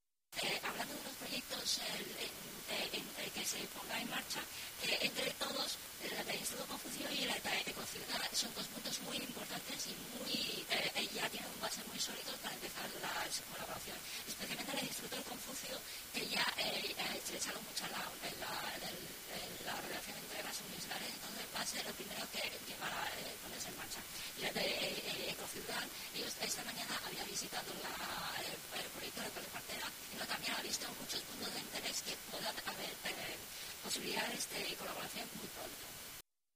Sobre este asunto, el vicealcalde de Zuhai, Pan Ming, ha manifestado: